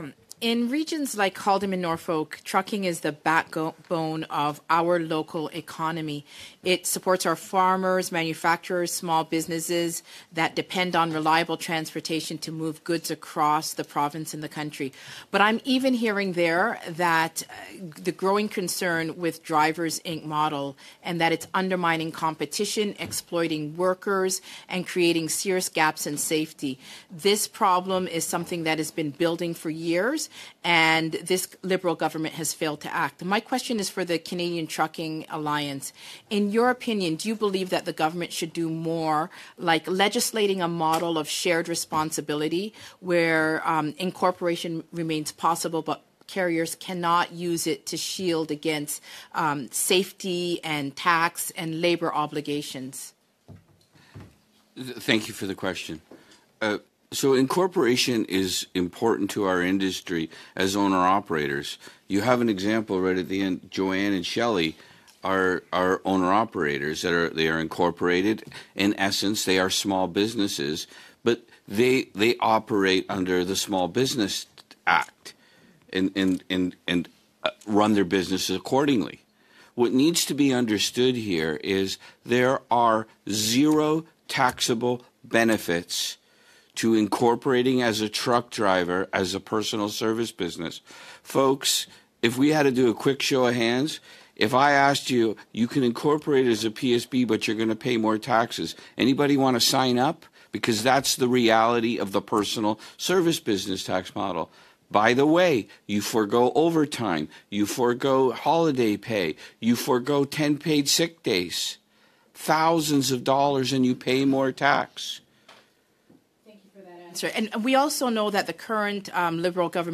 Click below to hear an audio clip from MP Leslyn Lewis’s exchange at a House of Commons committee this week regarding safety and enforcement in the trucking industry.